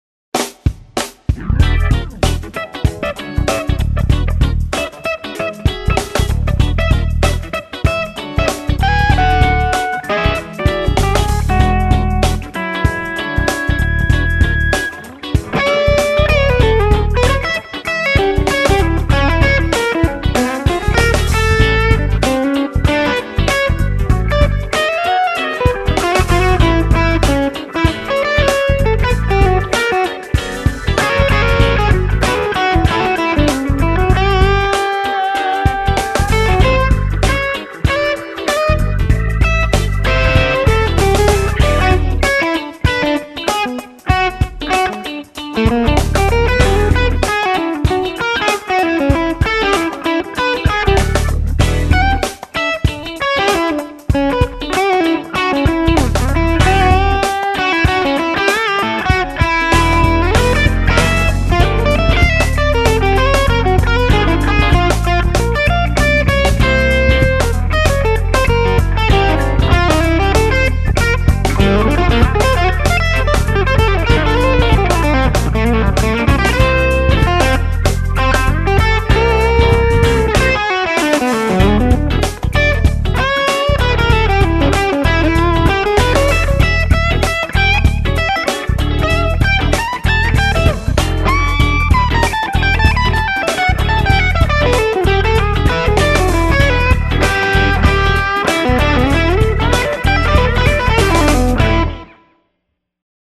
G1265 speaker. FatWerks clips have more gain than the Pimp clips. 200ms delay in mix for the pimps.
(mid trimmer at 10k, 1uf on V2b, JJ tube in V2)
The RFT sounds much thicker than the JJ altough I´ve got the feeling that the JJ was giving more sustain during the playing.